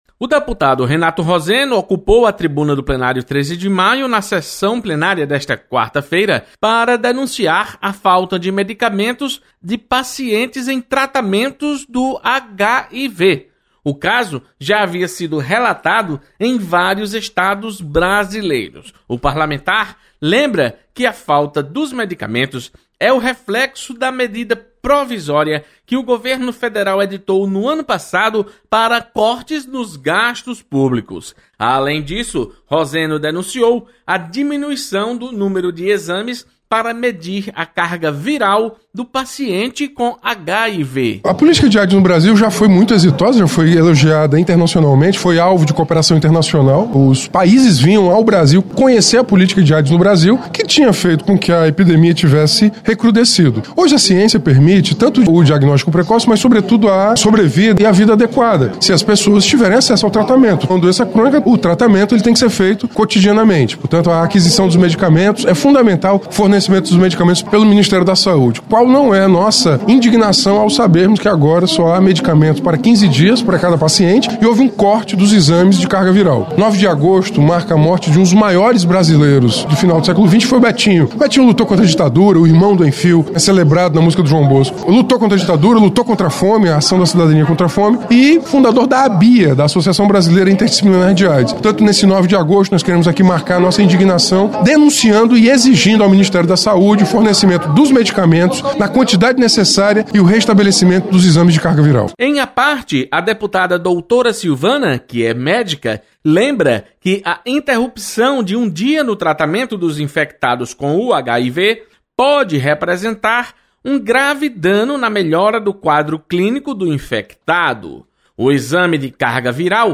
Deputado Renato Roseno denuncia falta de medicamento para o tratamento de pacientes com HIV.